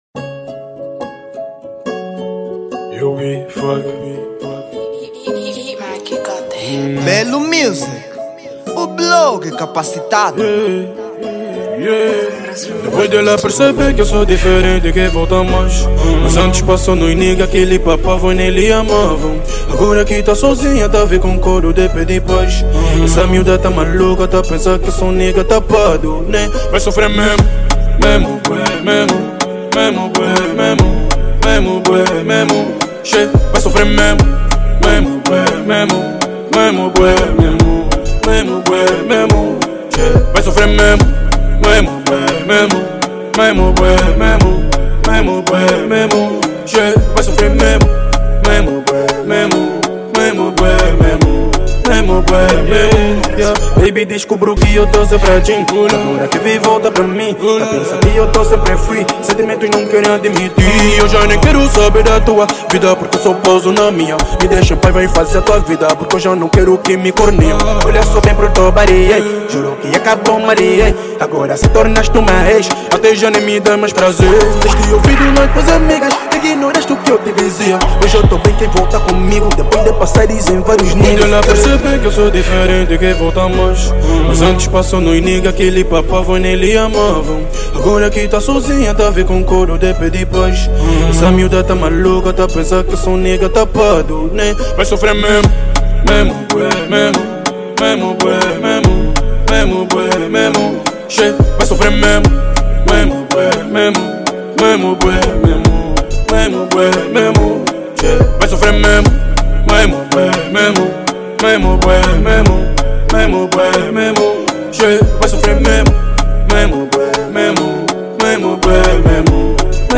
Género: rap